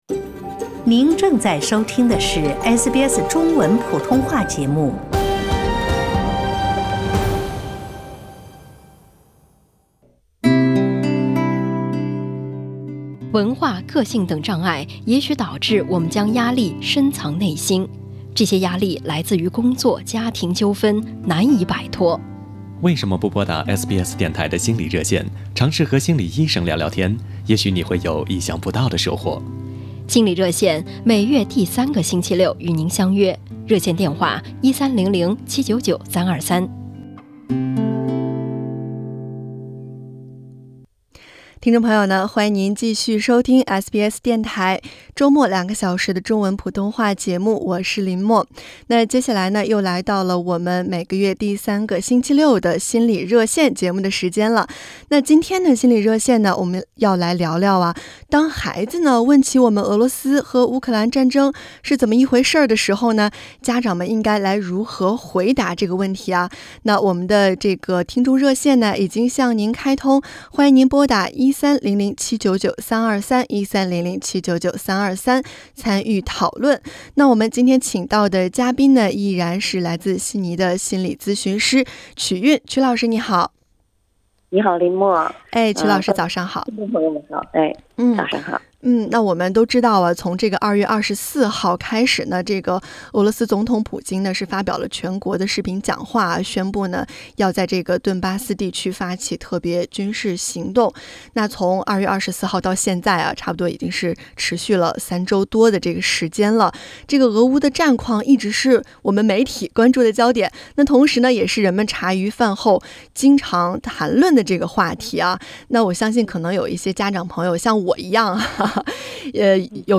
除此之外，在今天的热线节目中也有听众提出成年人打游戏上瘾该怎么解决？